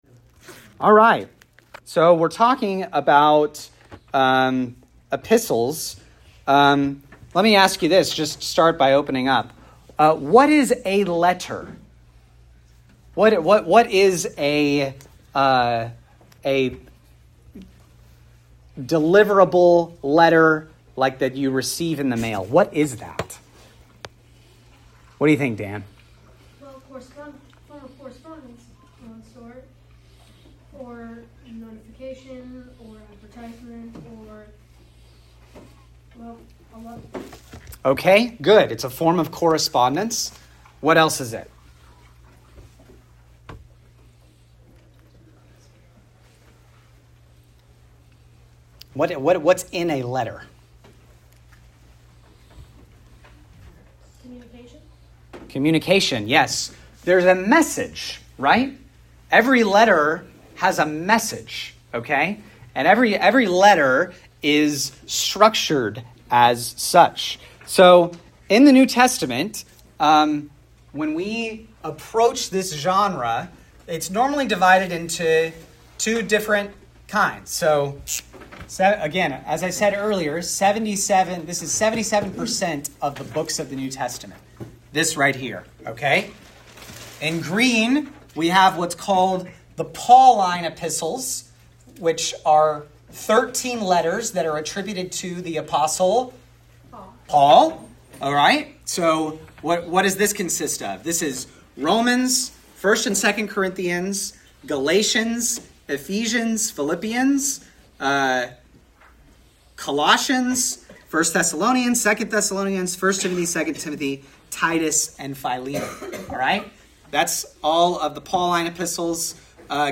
Equip Class: Opening the Scriptures - Lesson 7: The Epistles